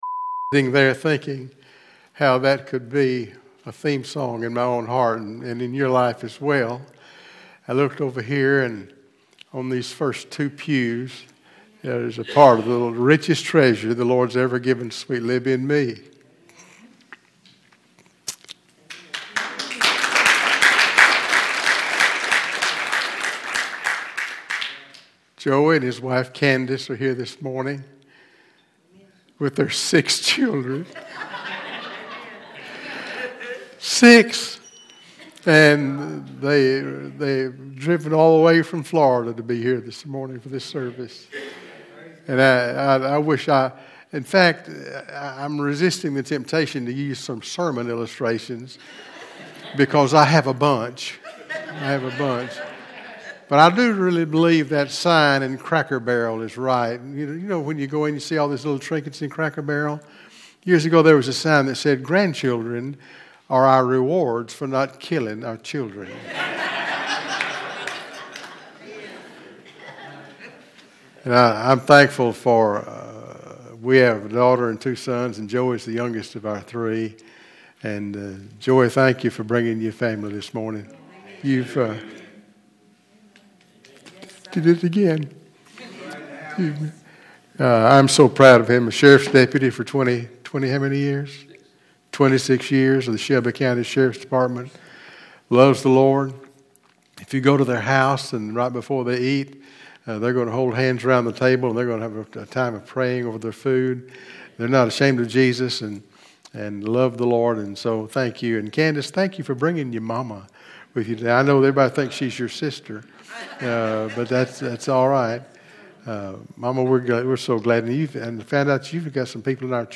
Sermons - Calvary Baptist Church